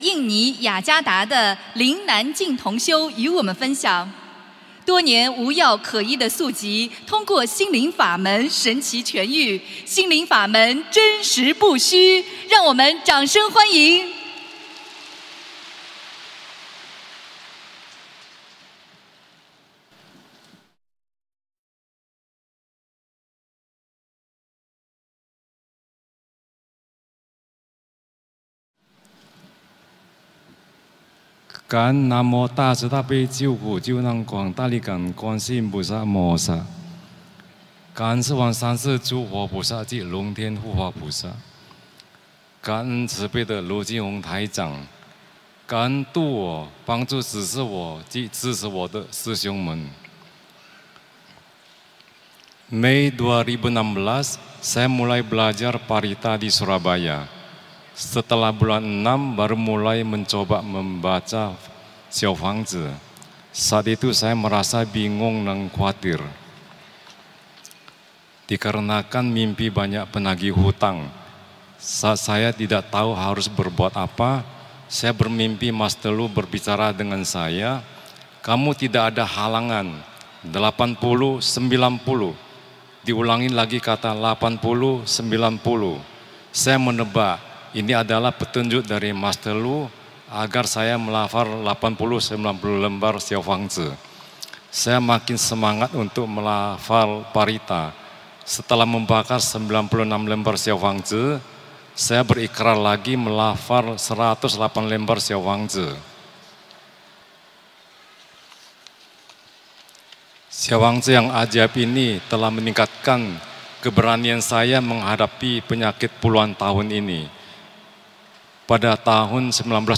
印尼雅加达【同修发言